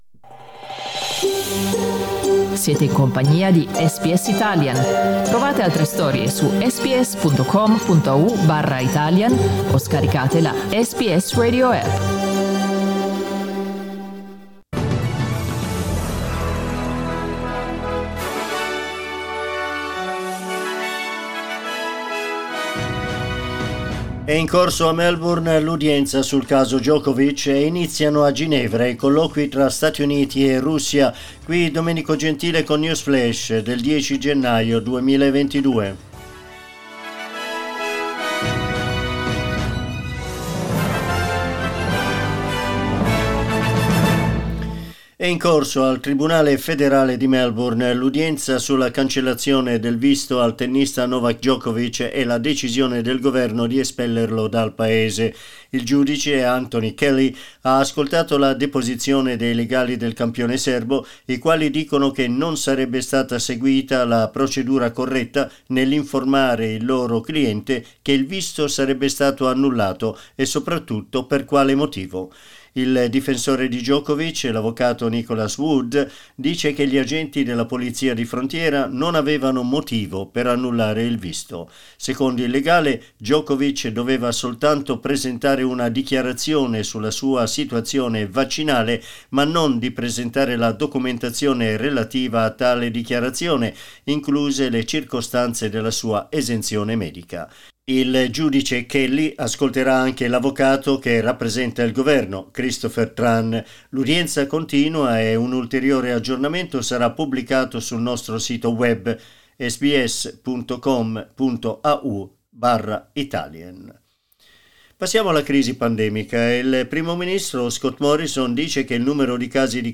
News flash 10 lunedì 10 gennaio 2022
L'aggiornamento delle notizie di SBS Italian.